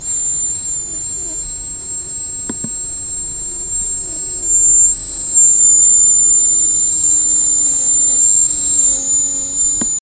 Annoying Whistle
Tags: annoying high pitched whistle whine